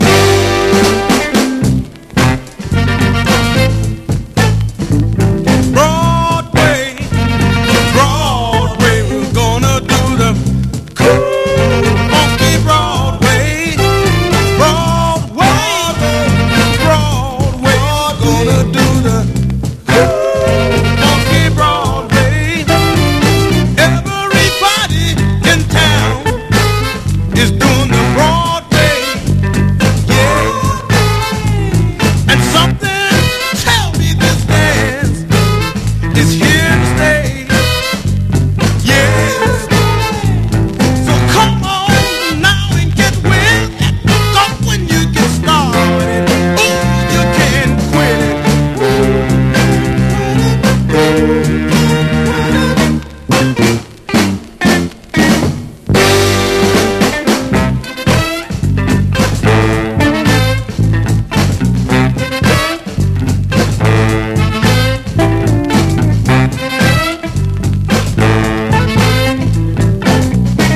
ジャズのフィーリングを持ったスウィート・ソウルから官能的なリズム&ブルース、ドゥーワップまで揃った最高なアルバムです。